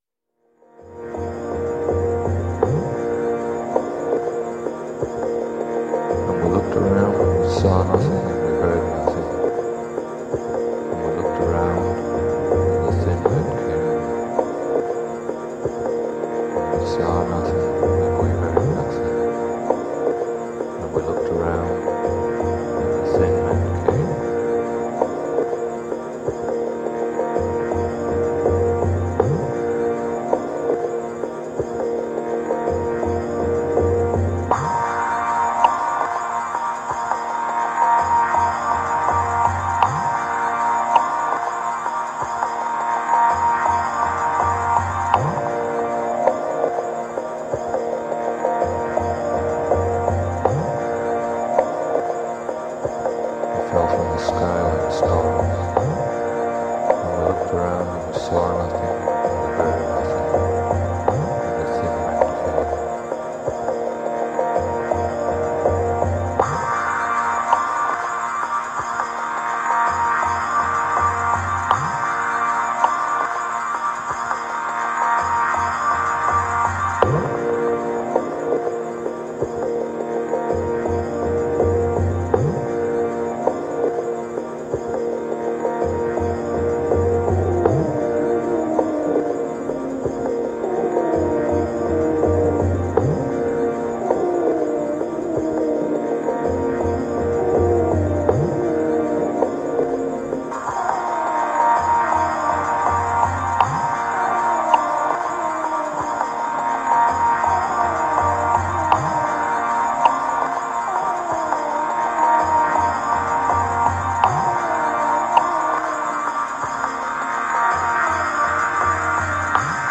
Ambient/world dream field.
Tagged as: Ambient, New Age, Industrial, Remix, Space Music